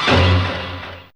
OrchFz1A3.wav